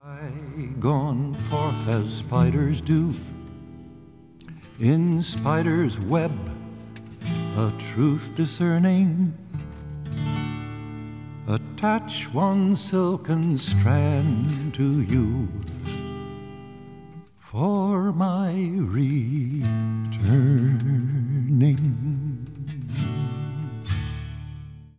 voice, 12-string guitar